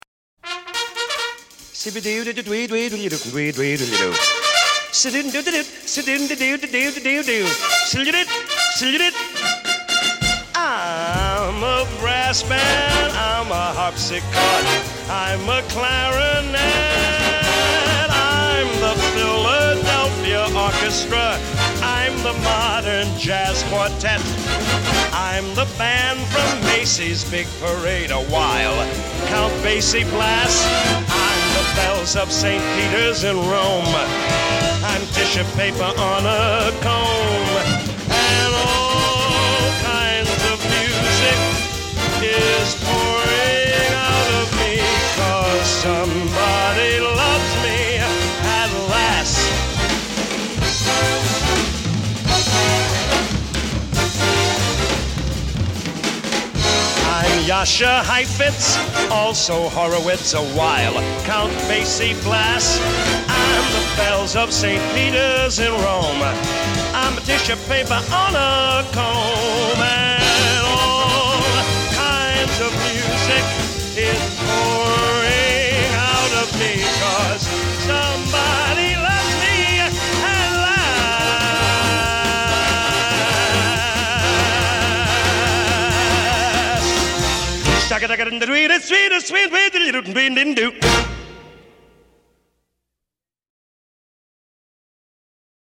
Genre: Musical